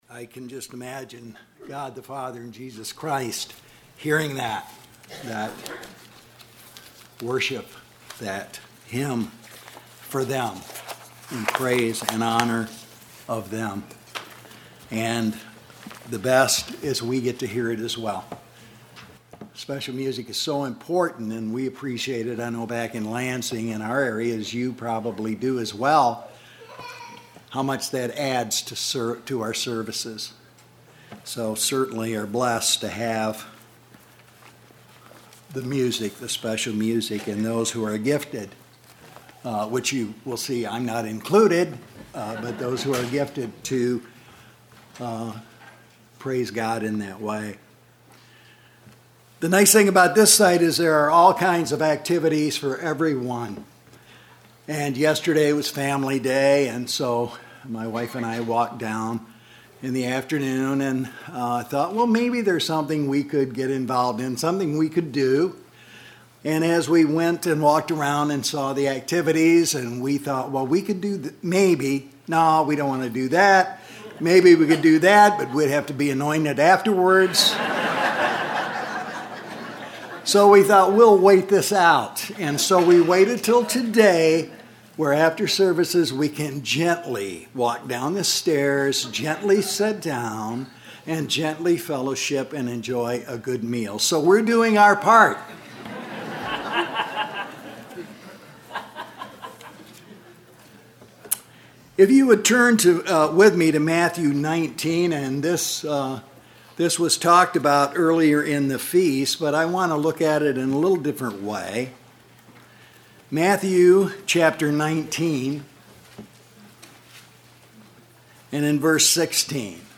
This sermon was given at the Lancaster, Pennsylvania 2019 Feast site.